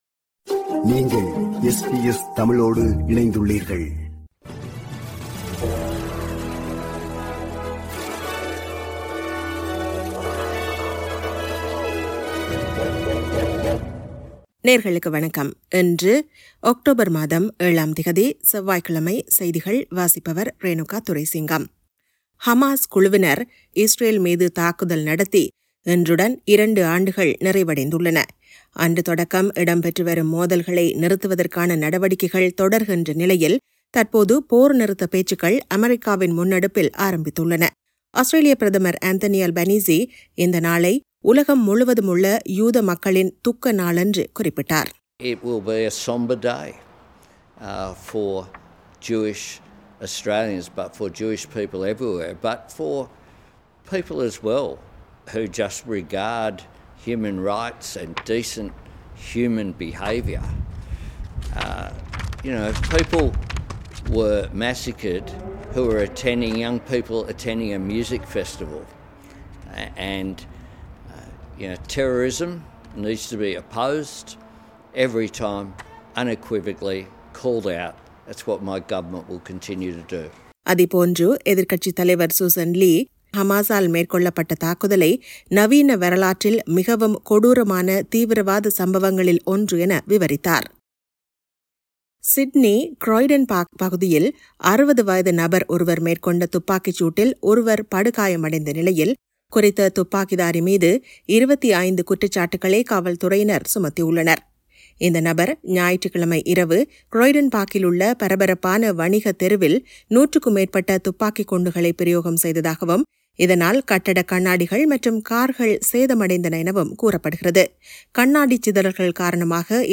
SBS தமிழ் ஒலிபரப்பின் இன்றைய (செவ்வாய்க்கிழமை 07/10/2025) செய்திகள்.